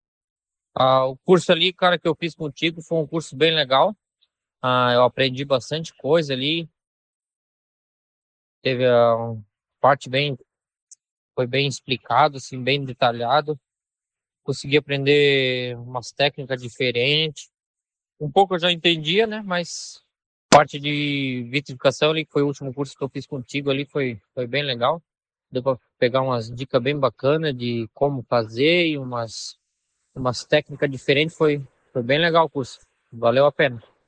Depoimentos em áudio